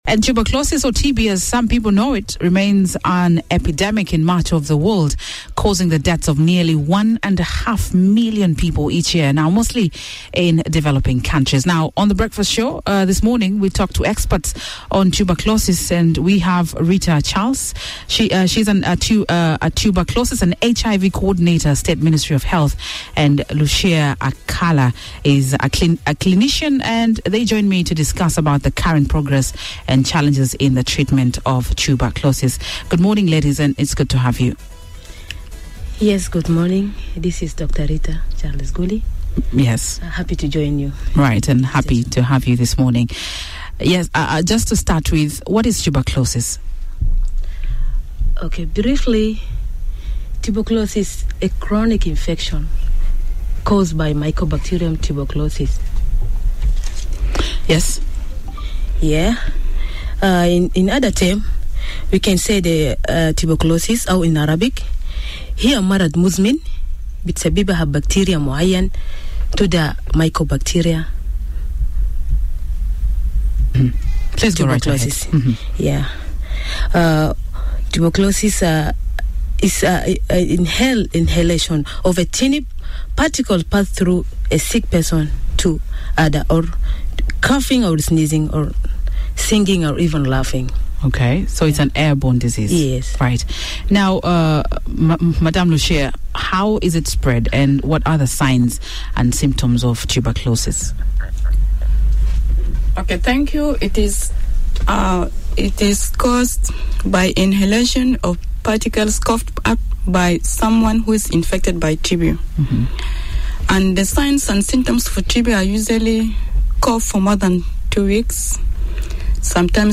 On the Breakfast show this morning, we talk to experts on Tuberculosis